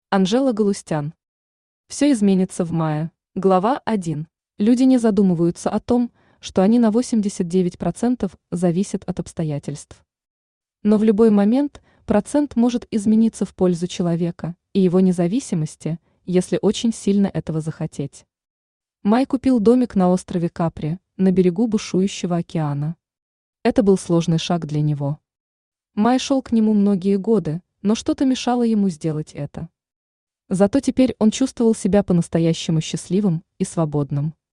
Аудиокнига Все изменится в Мае | Библиотека аудиокниг
Aудиокнига Все изменится в Мае Автор Анжела Галустян Читает аудиокнигу Авточтец ЛитРес.